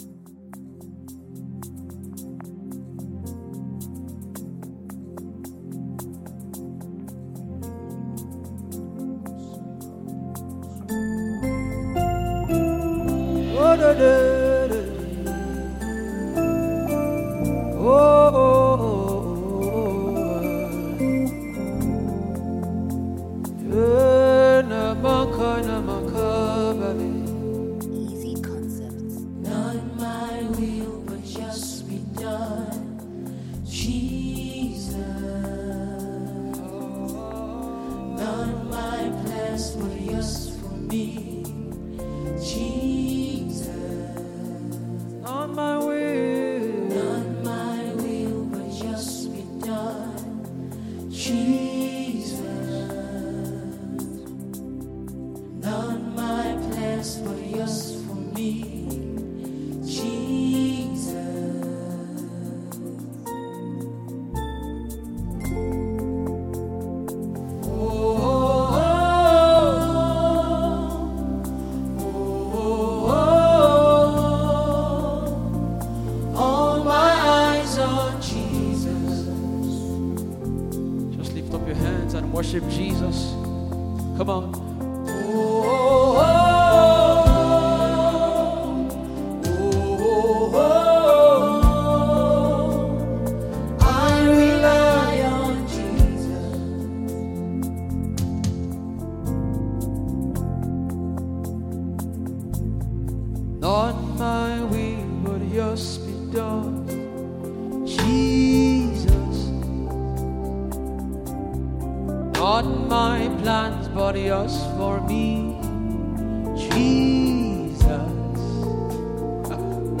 soul-stirring tune